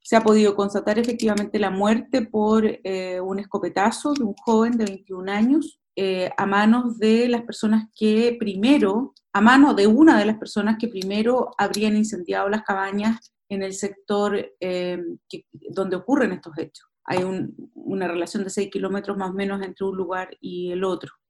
cuna-fiscal-regional-marcela-cartagena.mp3